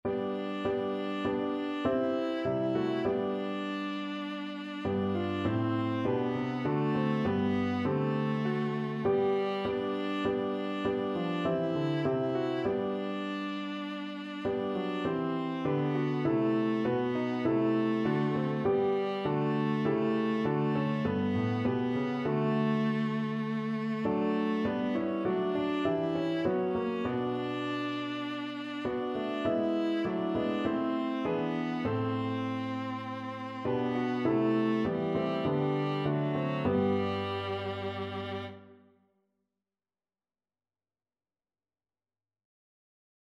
Christian
4/4 (View more 4/4 Music)
Classical (View more Classical Viola Music)